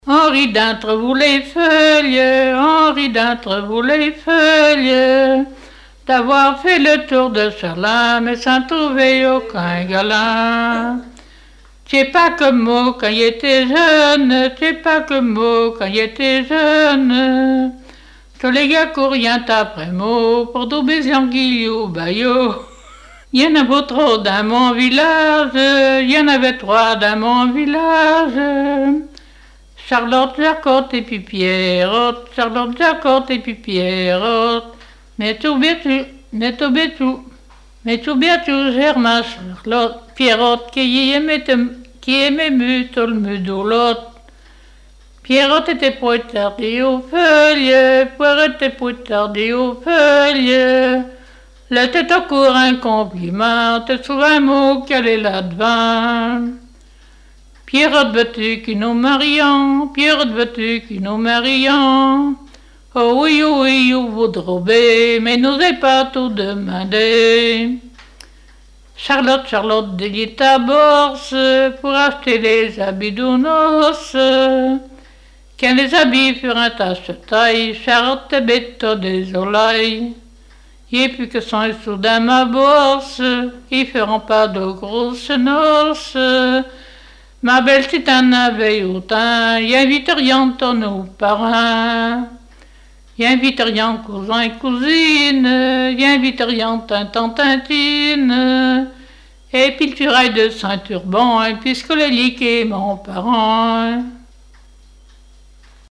Genre laisse
Enquête Arexcpo en Vendée
Pièce musicale inédite